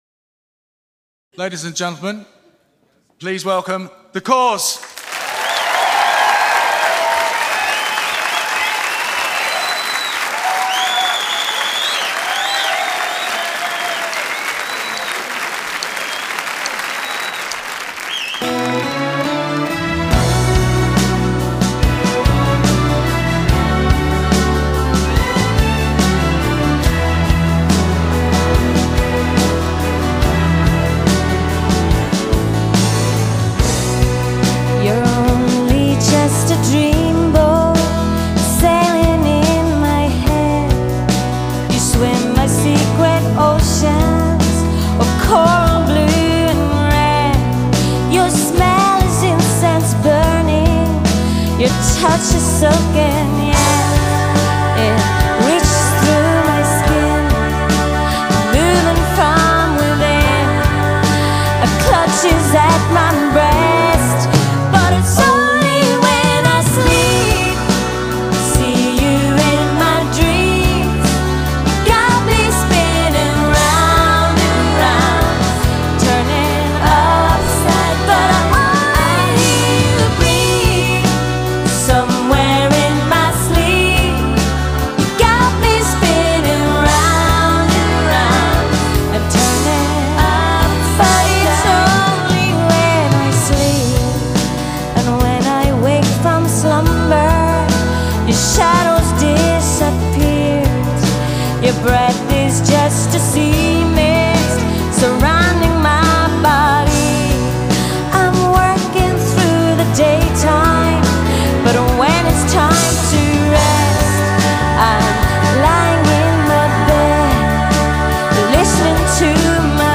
在乐队中担任小提琴手、配唱。
在乐队中担任领唱、口琴演奏、词作者
专辑流派：Pop